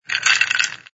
sfx_ice_moving01.wav